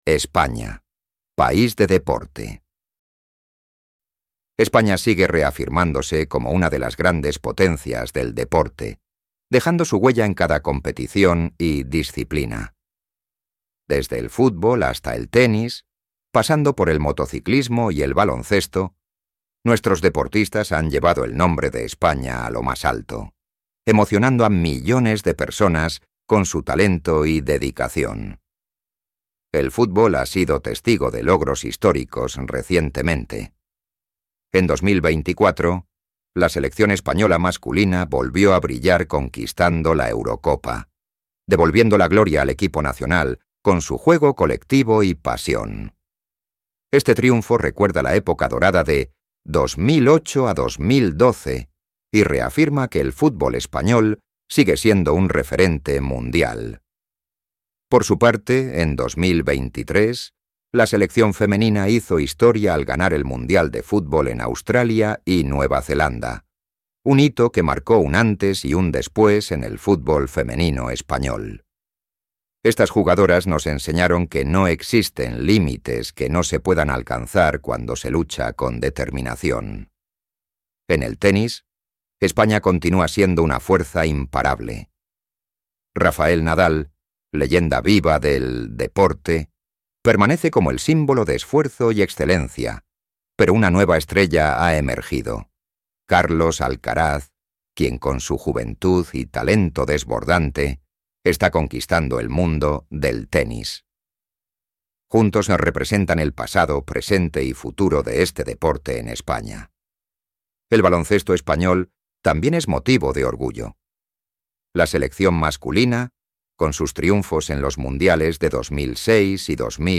Spanish online reading and listening practice – level B2
audio by a Spanish professional voice actor